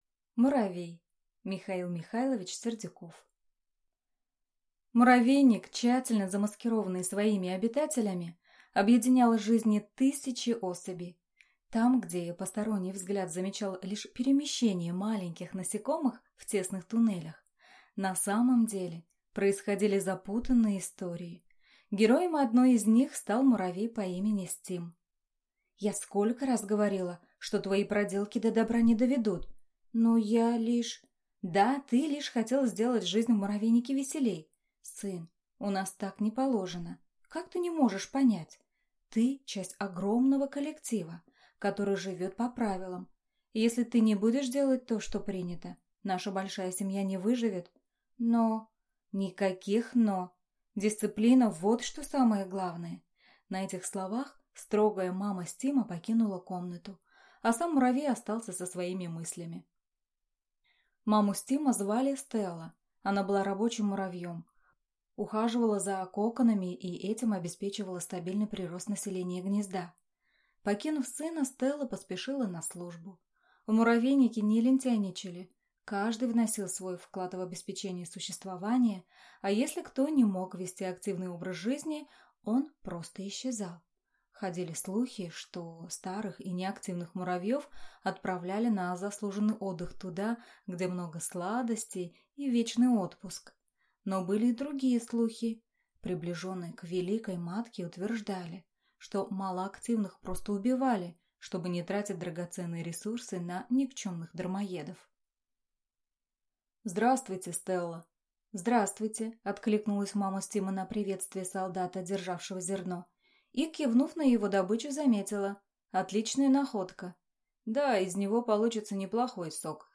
Аудиокнига Муравей | Библиотека аудиокниг